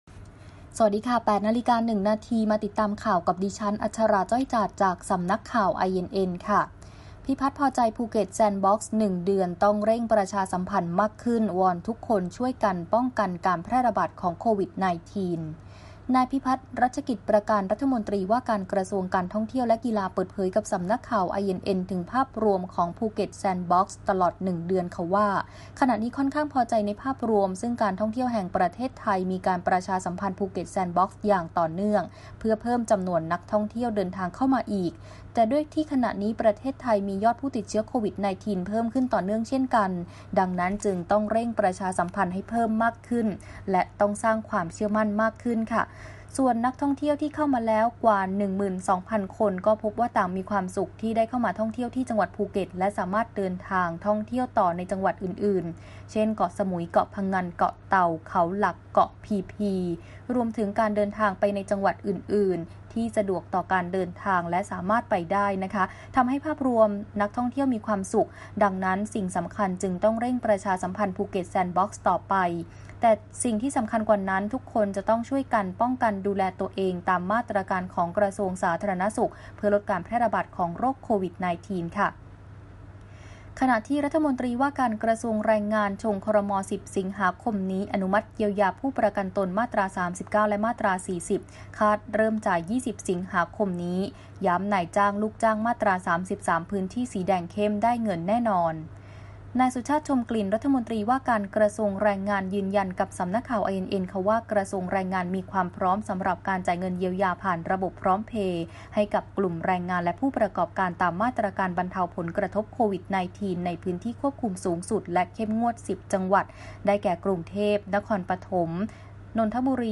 คลิปข่าวต้นชั่วโมง
ข่าวต้นชั่วโมง 08.00 น.